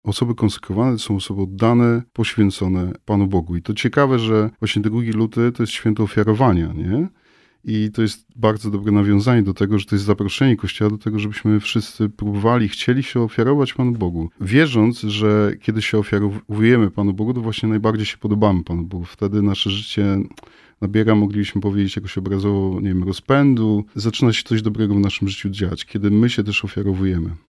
misjonarz klaretyn.